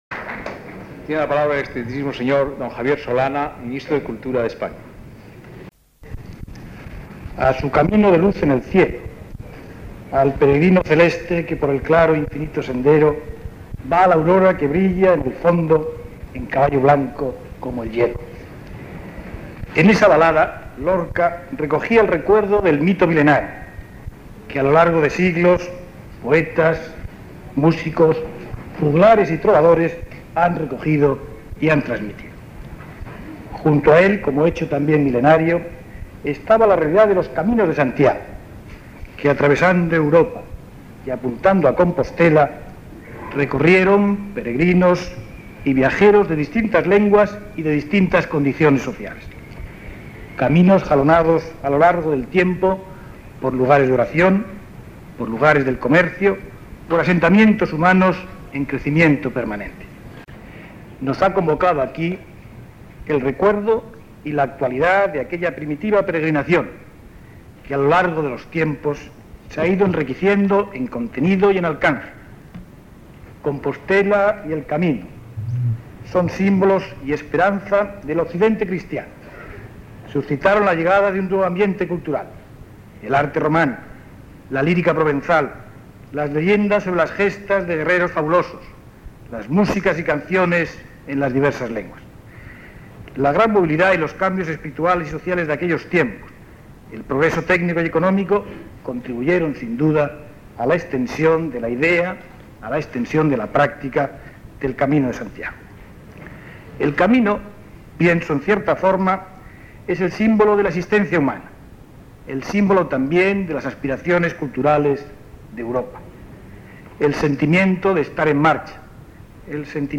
Discurso de D. Javier Solana, Ministro de Cultura de España
Acto de proclamación del Camino de Santiago como Itinerario Cultural Europeo. 1987
Actos de presentación